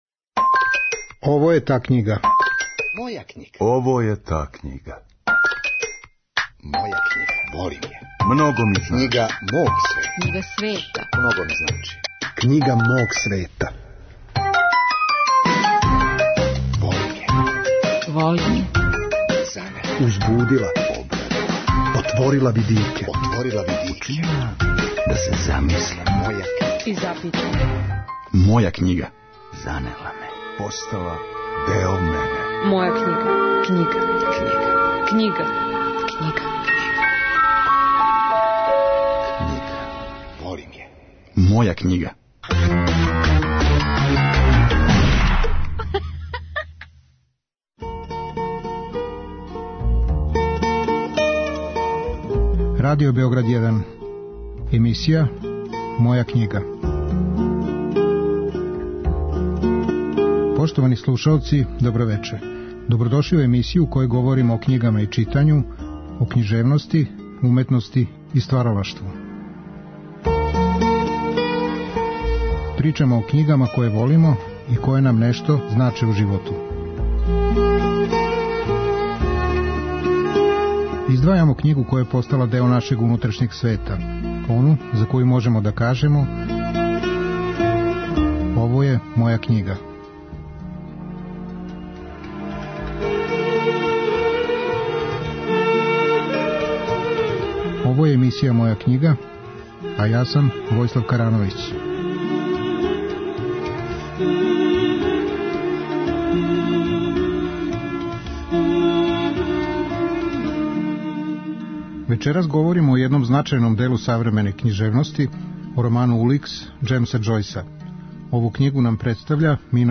У јулу слушамо емисије у којима саговорници говоре о изузетним делима светске књижевности.